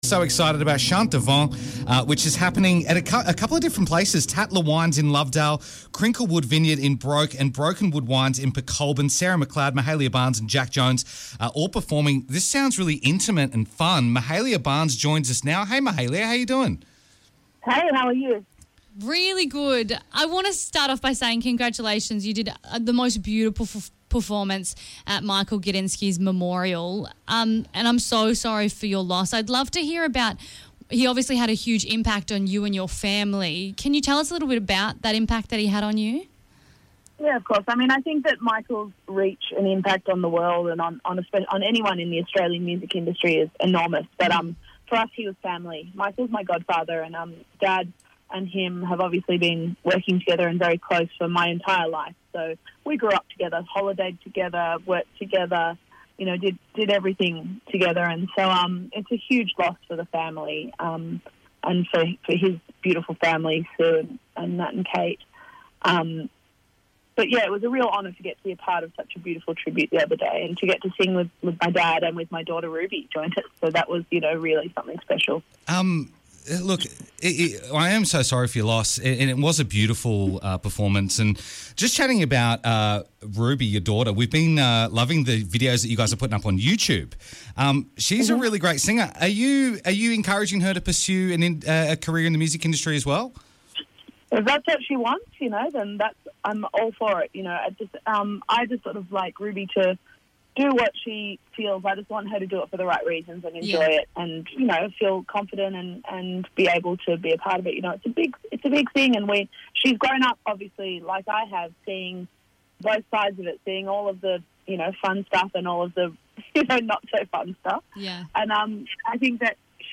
Mahalia joins us for a chat talking about her upcoming gigs in The Hunter Valley